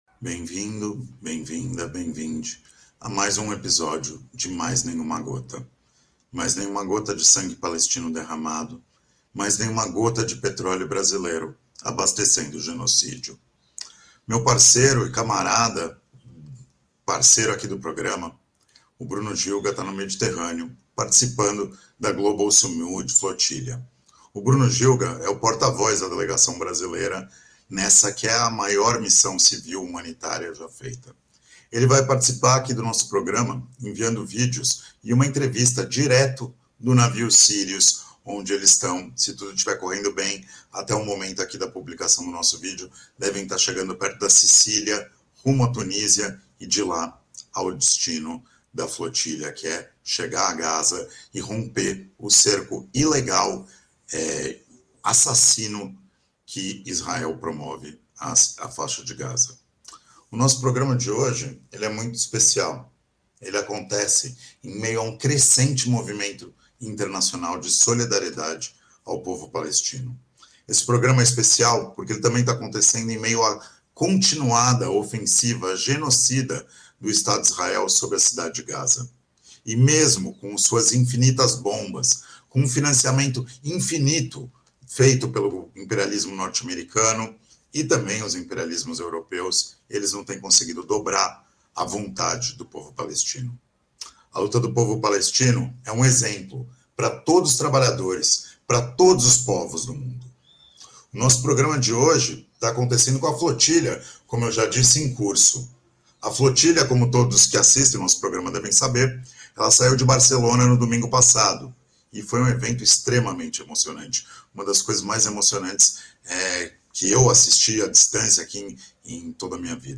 diretamente do barco Sirius, parte da Global Sumud ...